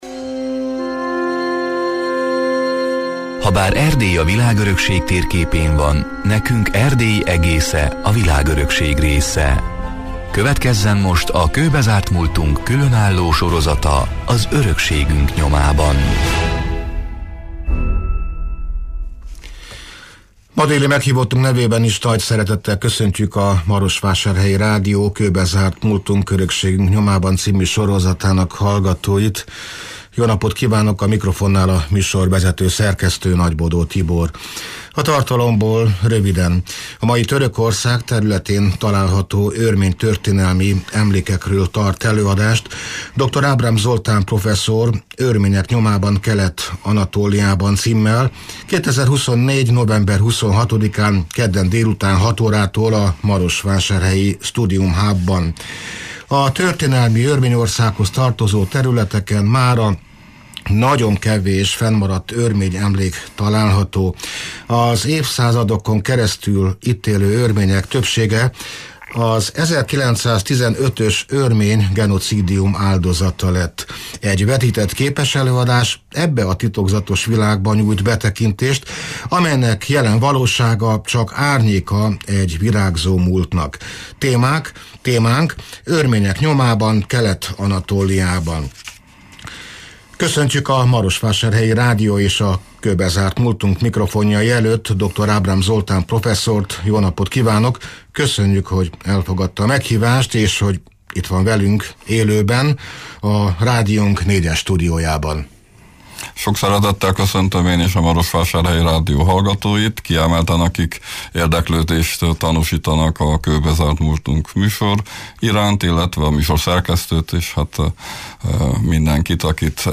(elhangzott: 2024. november 23-án, szombaton délben egy órától élőben)